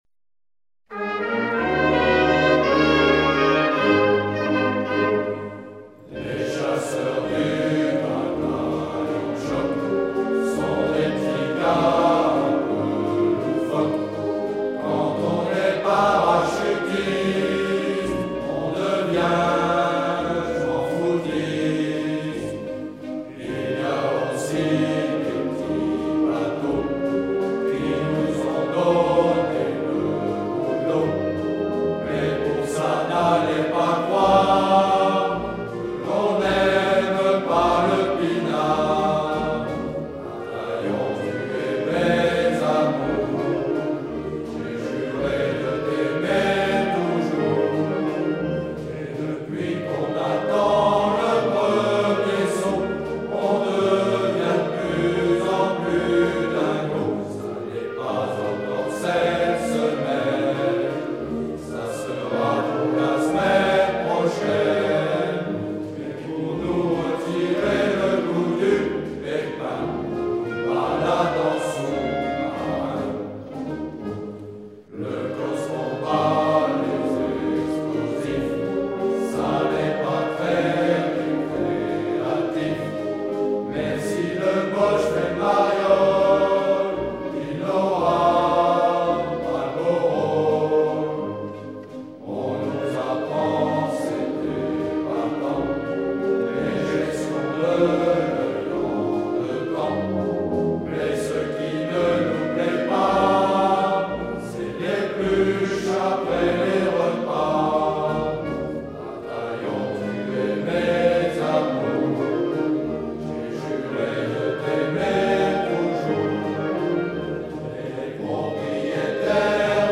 Son ton ironique et l’air de valse qui font l’originalité de cette chanson ont empêché sa diffusion dans le répertoire militaire, les soldats préférant la Marche du bataillon de choc (En pointe toujours) composée à la même époque pour la même unité.